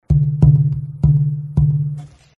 These are audio clips from the 2011 convention workshop.
European red spruce